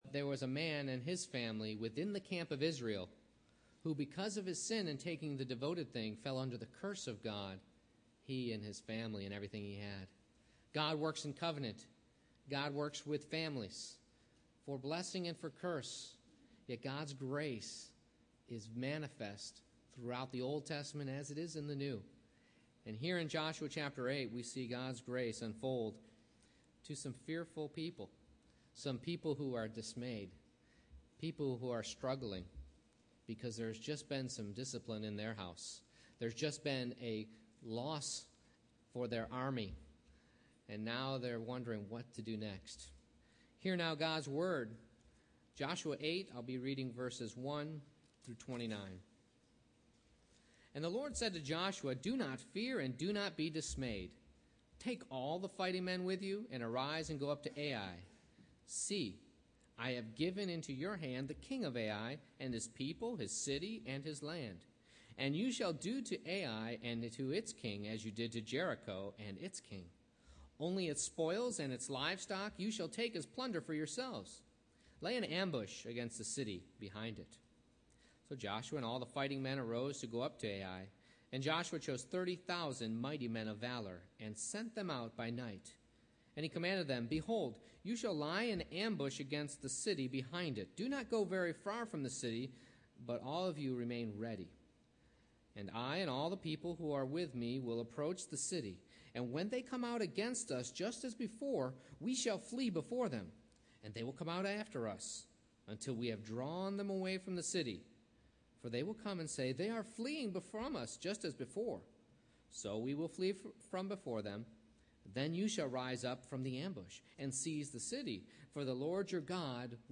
Joshua 8:1-29 Service Type: Morning Worship I. Rebuilding Trust A. Another Eye Exam B. A Change of Plan II.